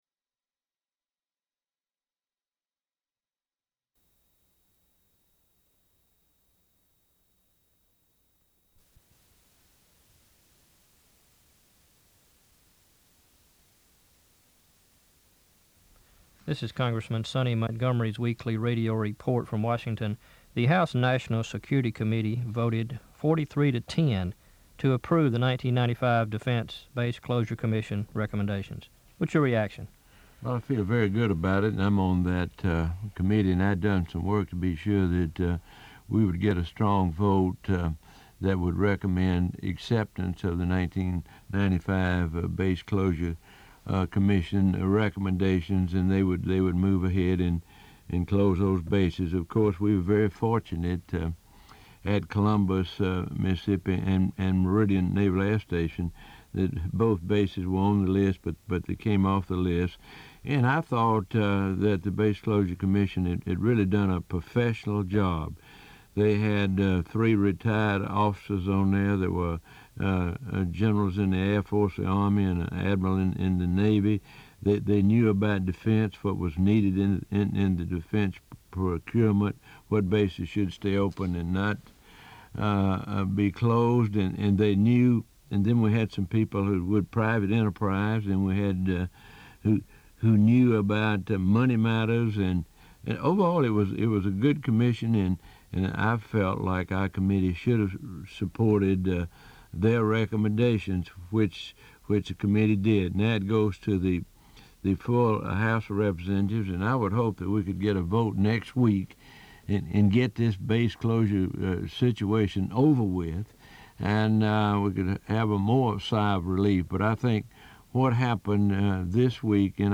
Weekly Radio Addresses